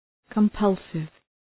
{kəm’pʌlsıv}
compulsive.mp3